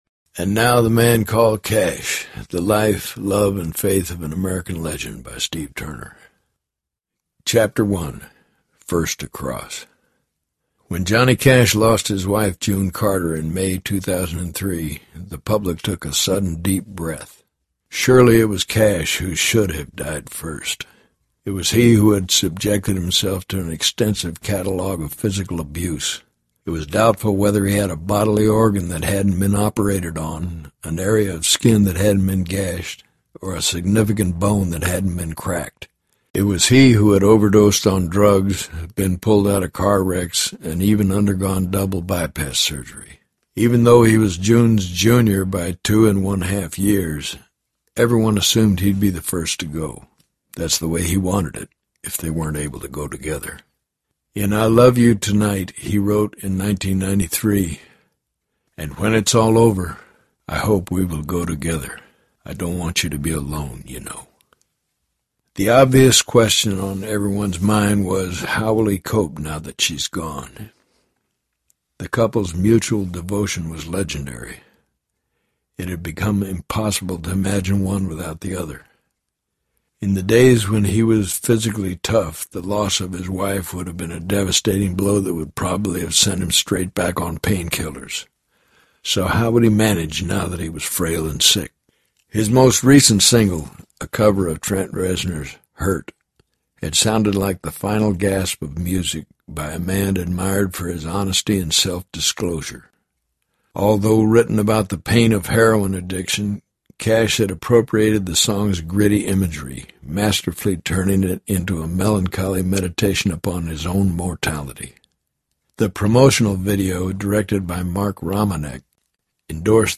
The MAN Called CASH Audiobook
Narrator
Kris Kristofferson
3.3 Hrs. – Abridged